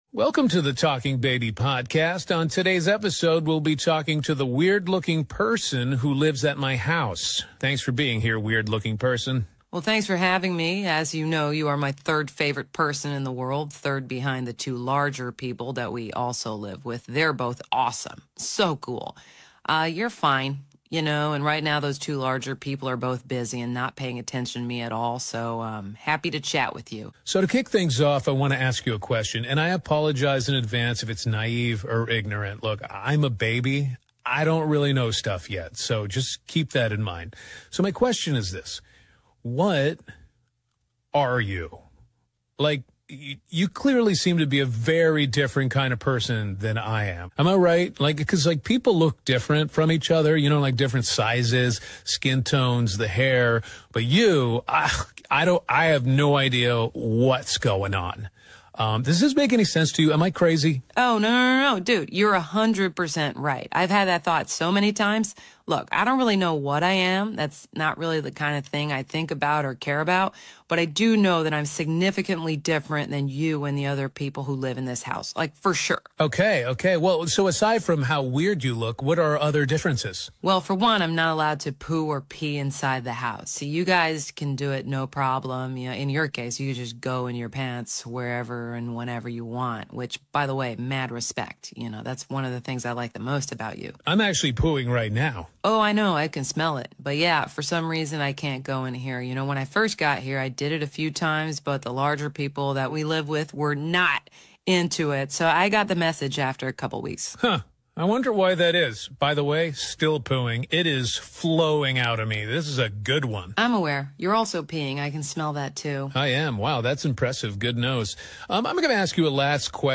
Cartoon cuteness meets deep baby–puppy banter.